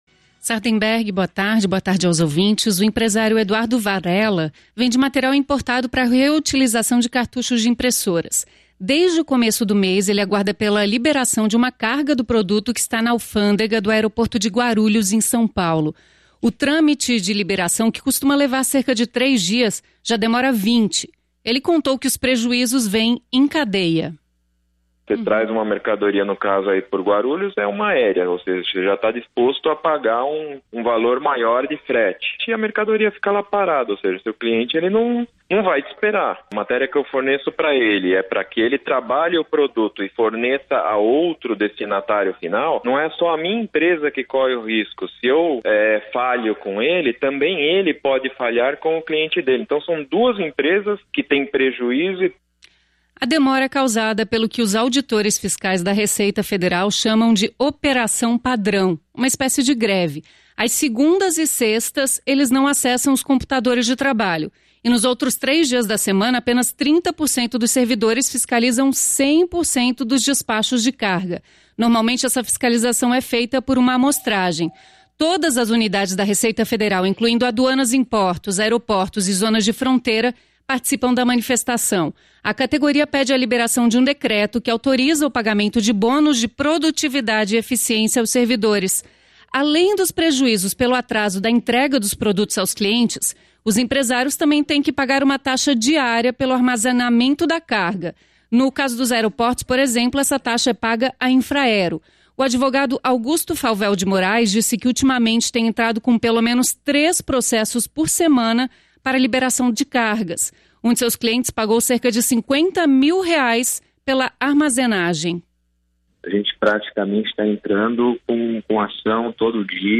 *Entrevista disponibilizada no dia 23/03/2018.